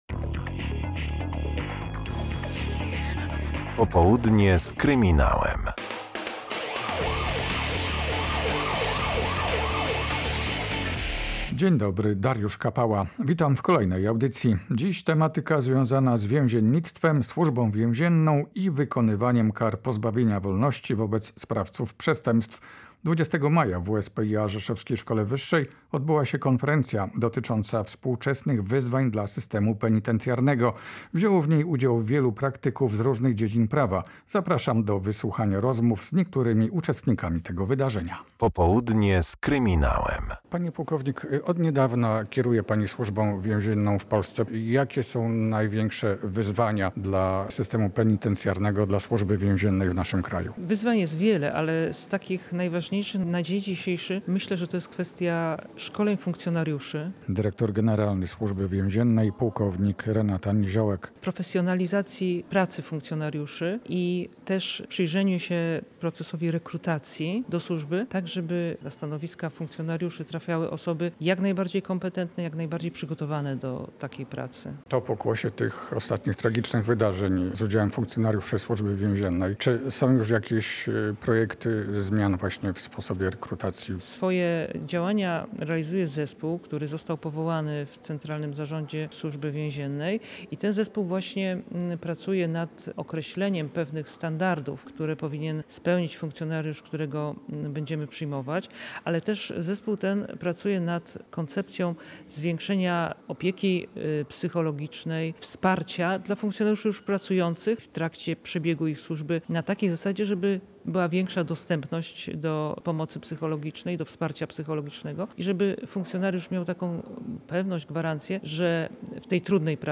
W WSPIA Rzeszowskiej Szkole Wyższej zorganizowano konferencję na temat współczesnych wyzwań dla systemu penitencjarnego. W audycji obszerna relacja z tego wydarzenia i rozmowa z nową szefową Służby Więziennej.